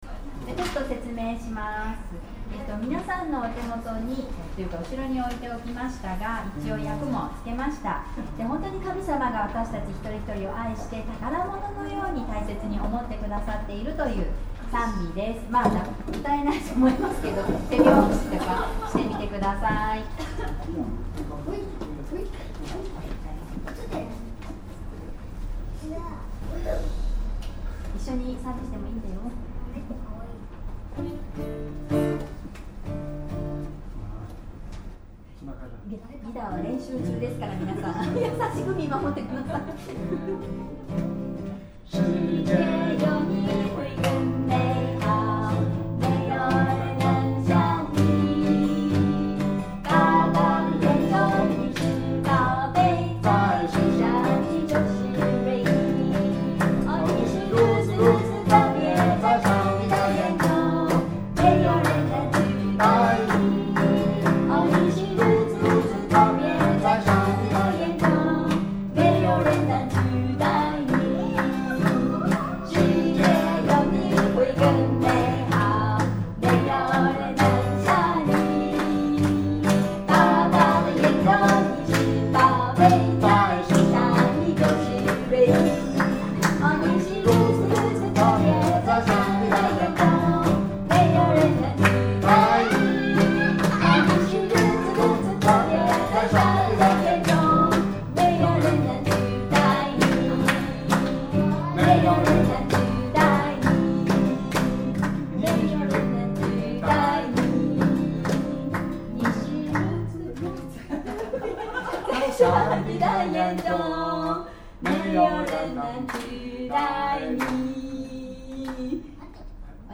♪特別賛美